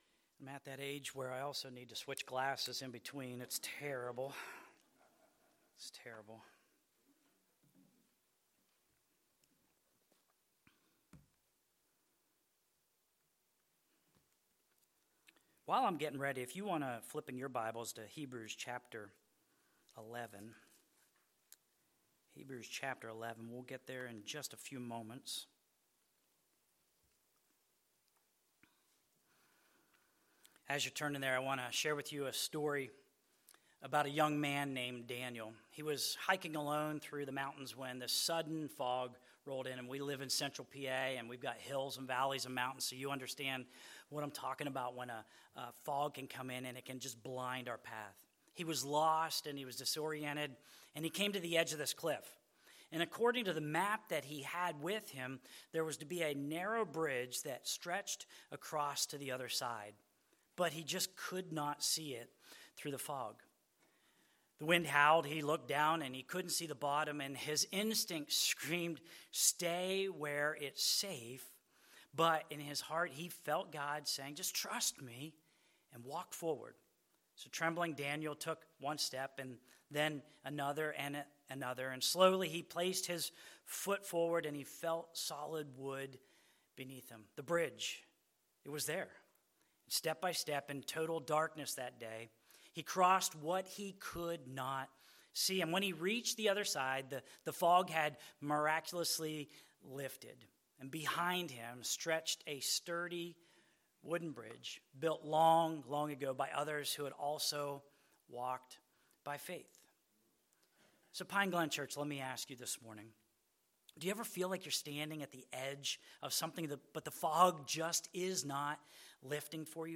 Service Type: Sunday Morning Services